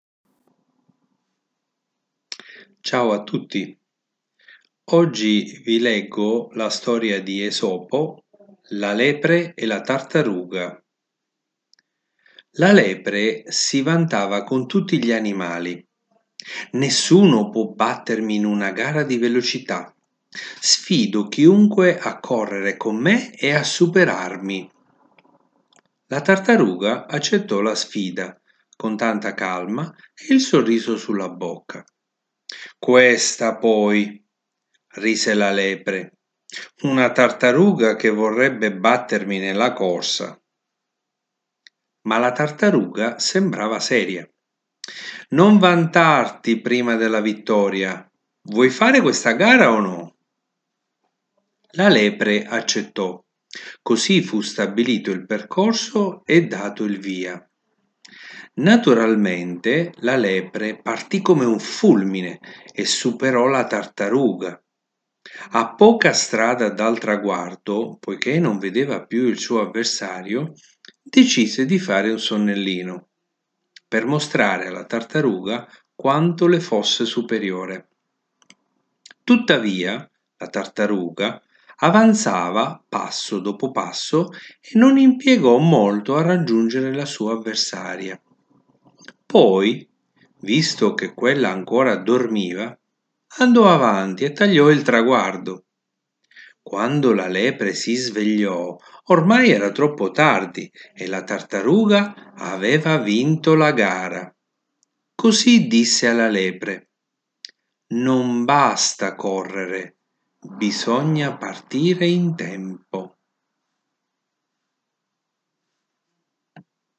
Oggi ho il piacere di leggervi la storia di Esopo “La lepre e la tartaruga”